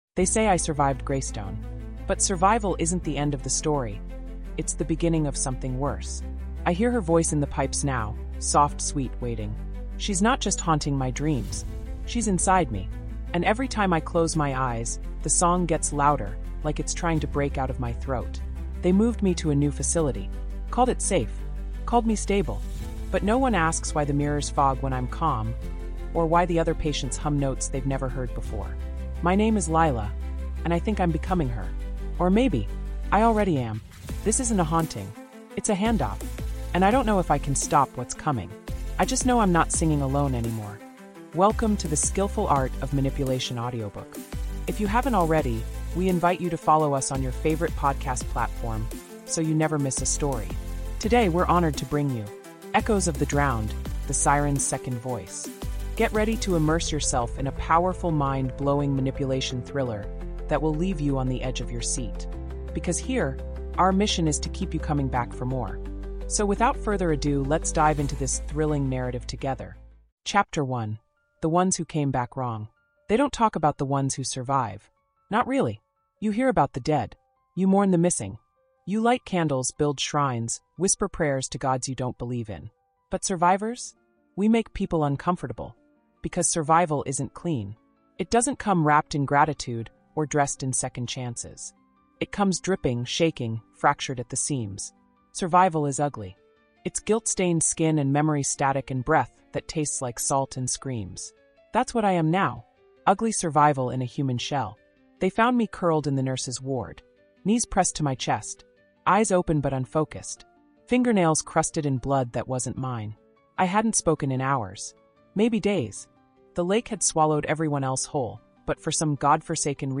Echoes of the Drowned | The Siren’s Second Voice | Audiobook
Psychological Thriller | Supernatural Mystery | Mythic Horror After surviving the legendary collapse of Greystone, Lila Farren is transferred to a new facility, branded a victim.
Told through immersive first-person narration, Echoes of the Drowned is a psychological descent into identity, myth, and the seductive pull of memory.